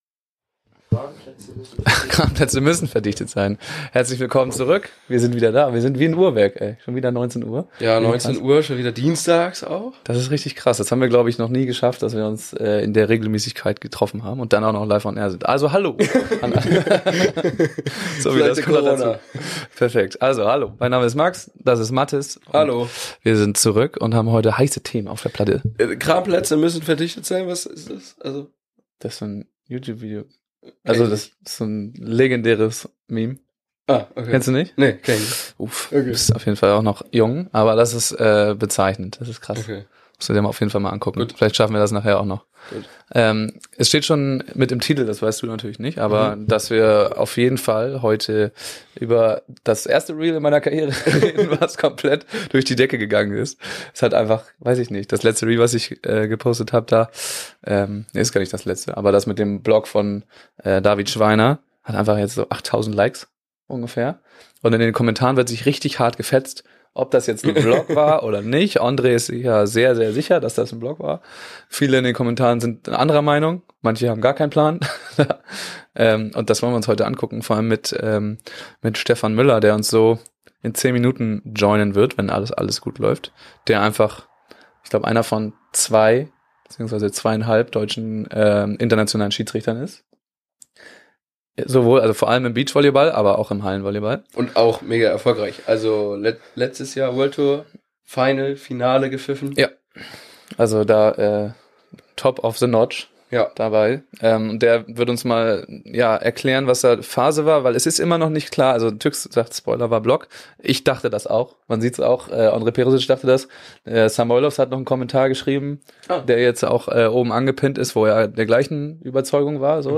Die Twitch Sendung als Podcast aufgearbeitet!